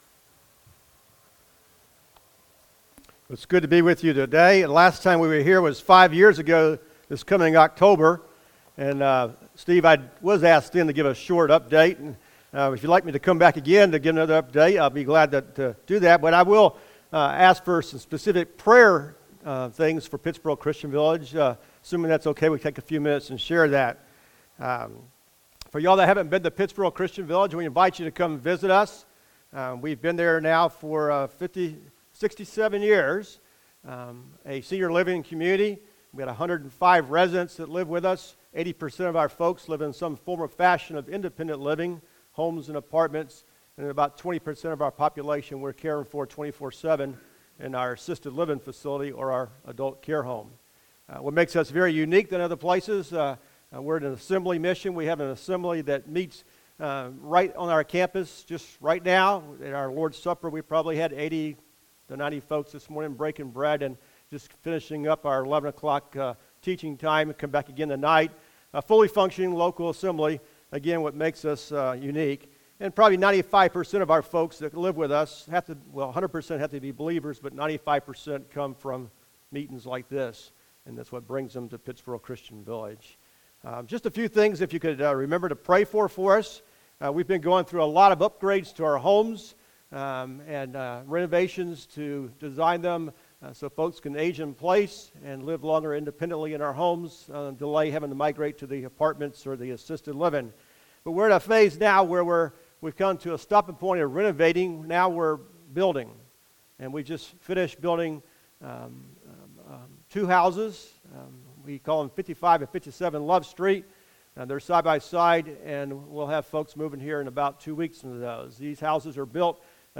The First Sermon